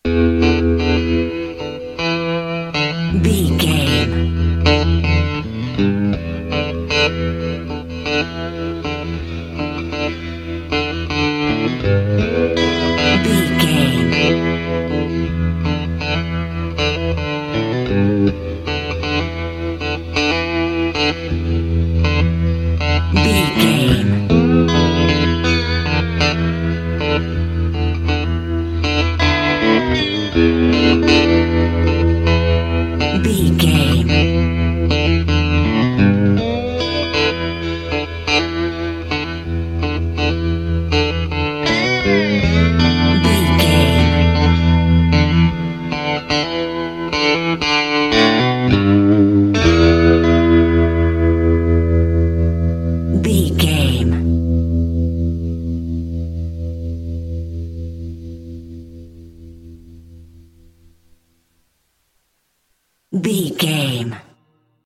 Aeolian/Minor
electric guitar
bass guitar
drums